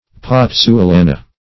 Search Result for " pozzuolana" : The Collaborative International Dictionary of English v.0.48: Pozzuolana \Poz`zu*o*la"na\, Pozzolana \Poz`zo*la"*na\, n. [It.] Volcanic ashes from Pozzuoli, in Italy, used in the manufacture of a kind of mortar which hardens under water.
pozzuolana.mp3